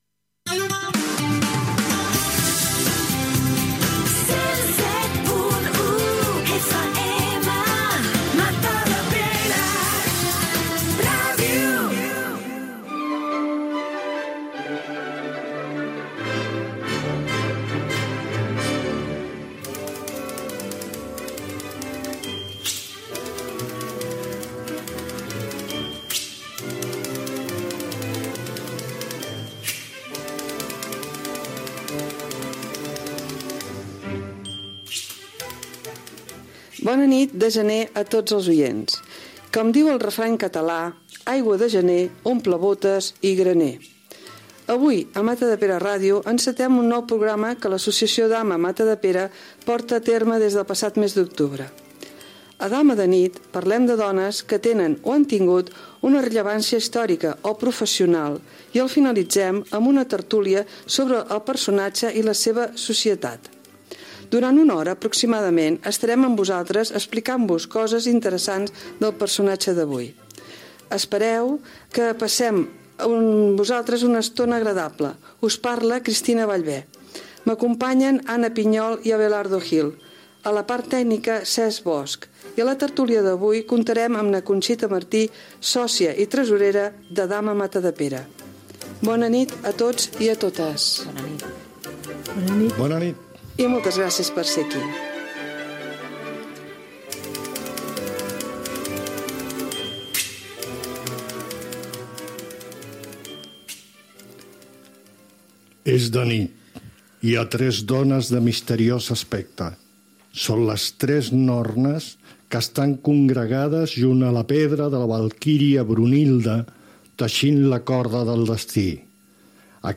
Indicatiu de l'emissora. Fragment del primer programa de l'associació Dama Matadepera, on parlen de dones que tenen una rellevància històrica o professional.
Presentador/a